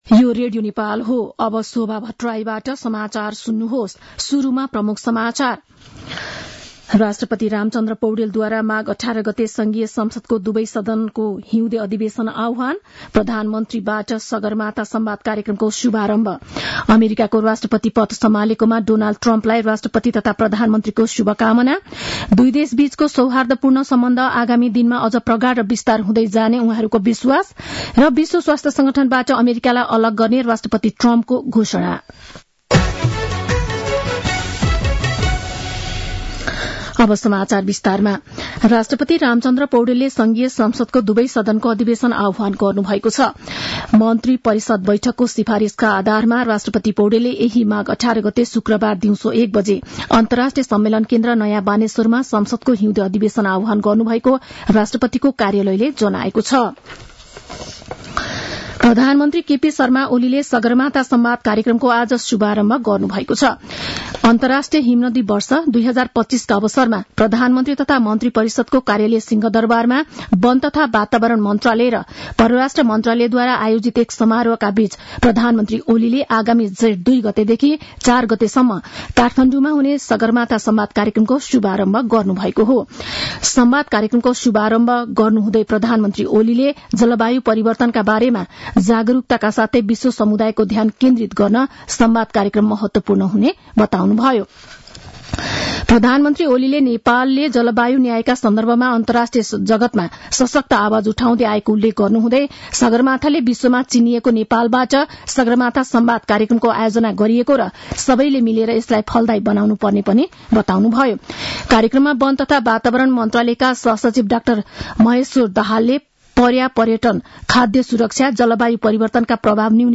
दिउँसो ३ बजेको नेपाली समाचार : ९ माघ , २०८१